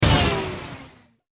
На этой странице собраны звуки, связанные с отключением электричества: резкие щелчки рубильников, затихающее гудение техники, тревожные перебои напряжения.
Питание исчезло